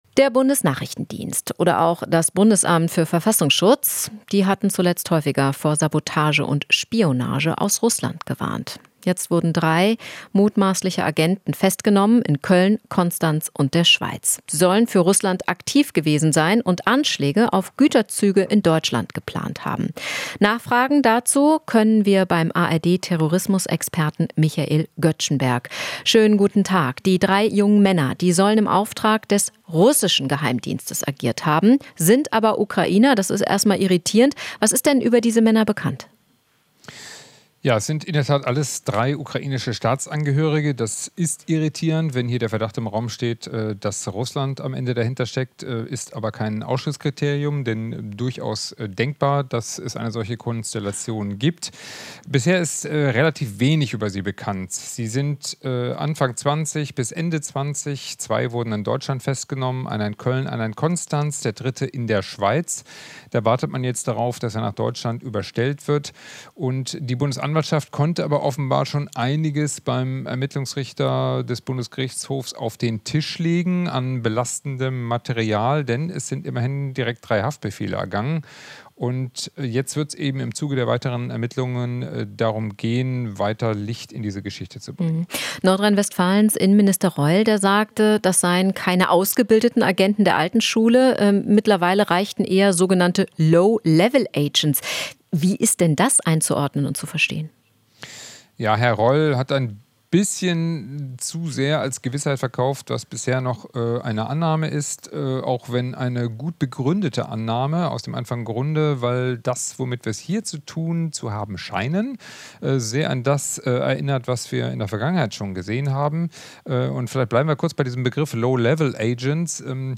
Interview - Drei Ukrainer wegen Sabotage-Plänen in Deutschland festgenommen